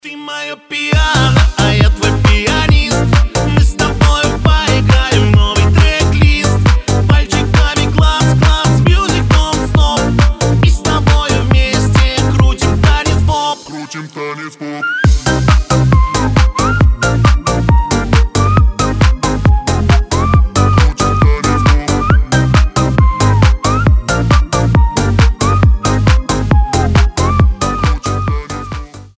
• Качество: 320, Stereo
позитивные
свист
веселые
заводные